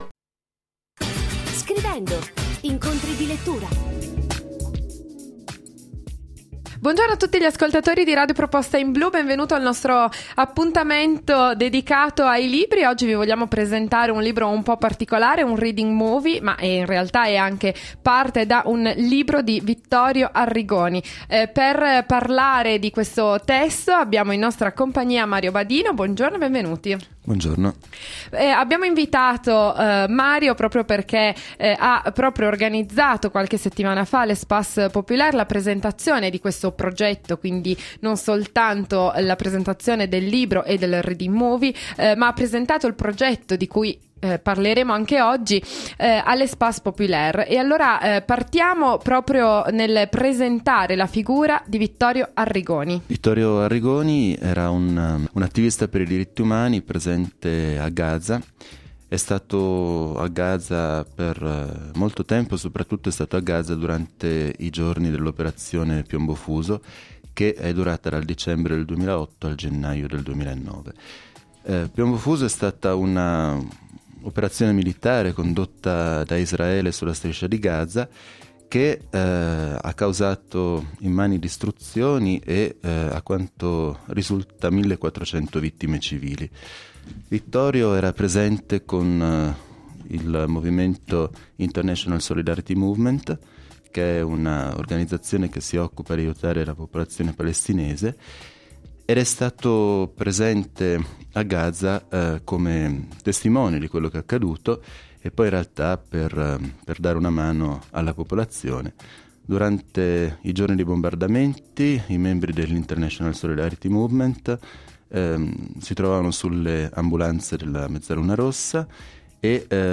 Al telefono con noi